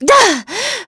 Veronica-Vox_Attack2.wav